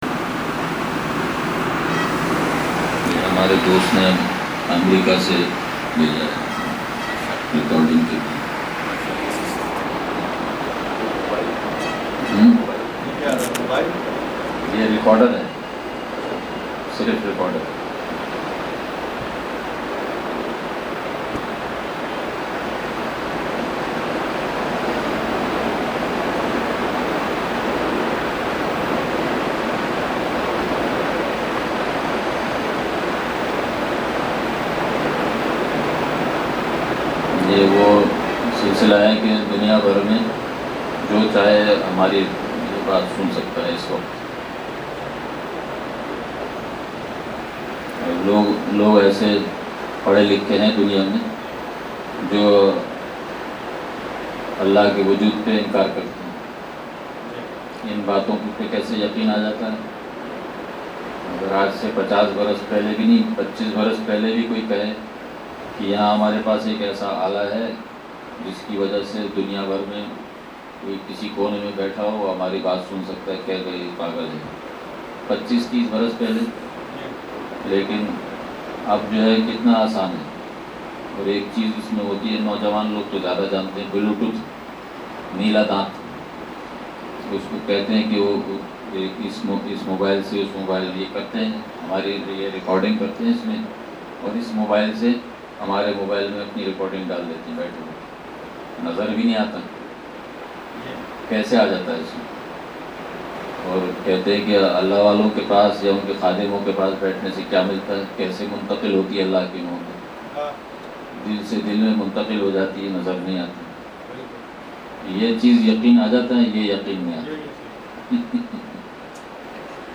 بیان – لانڈھی